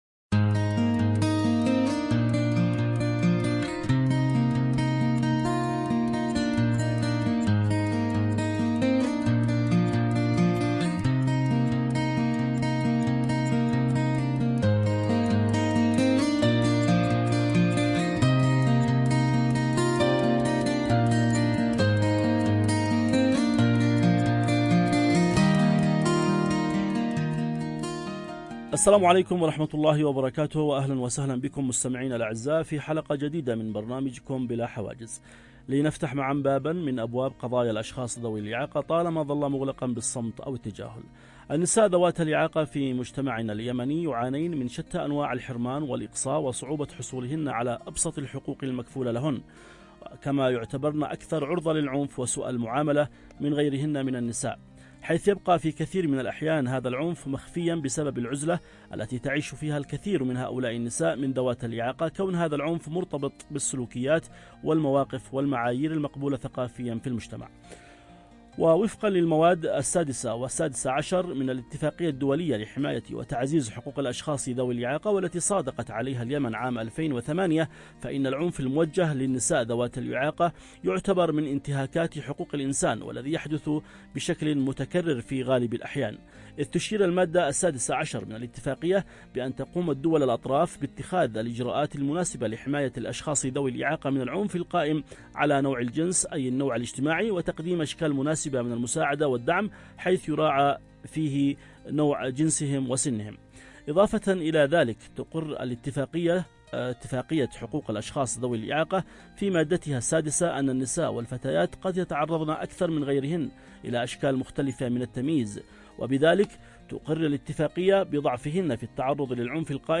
📅 الموعد: يوم السبت ⏰ الساعة: 11:00 صباحًا 📻 المكان: عبر أثير إذاعة رمز ندعوكم للمشاركة بآرائكم وتجاربكم